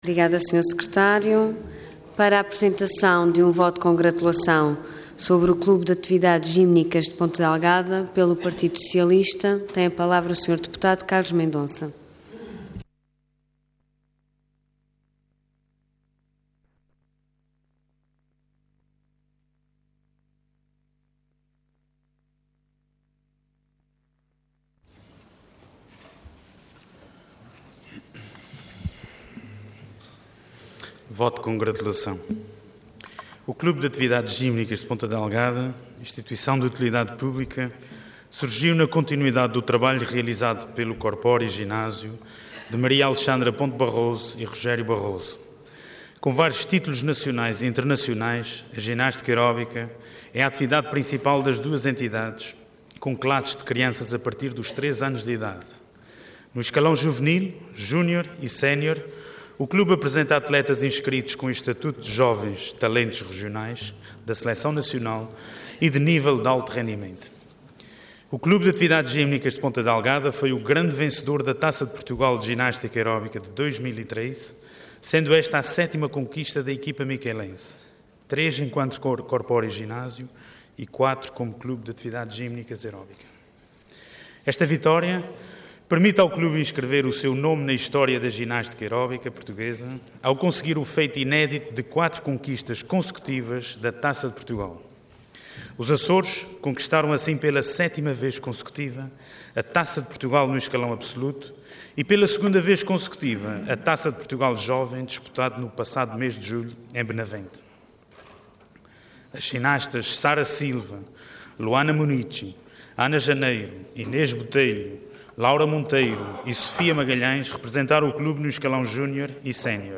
Intervenção Voto de Congratulação Orador Carlos Mendonça Cargo Deputado Entidade PS